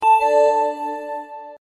windows-notification_24954.mp3